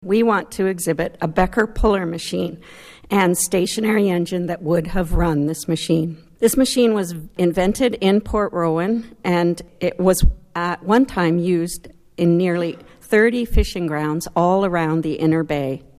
who spoke at council on Tuesday.